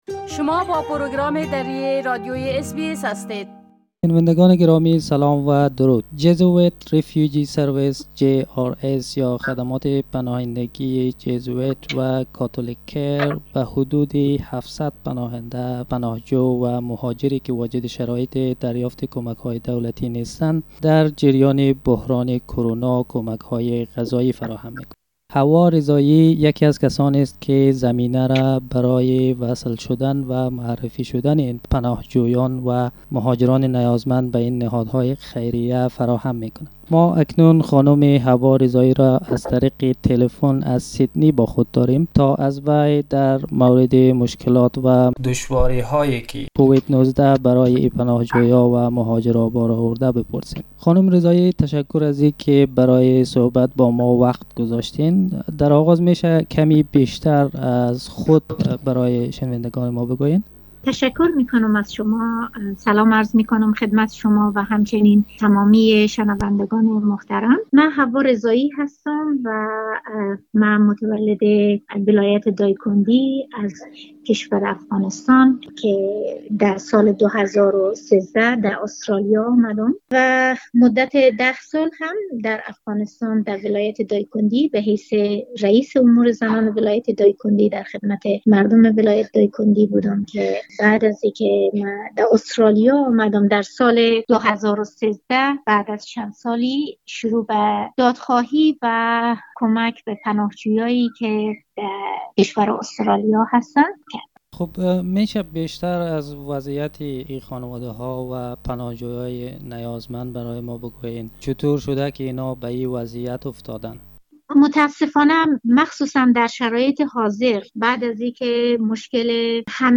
ج‌آراس و کاتولیک کیر به همکاری هم هفته‌وار برای حدود ٧٠٠ پناهجو و دارنده ویزه موقت کمک‌های غذایی فراهم می‌کنند. گفت‌وگوی اس‌بی‌اس دری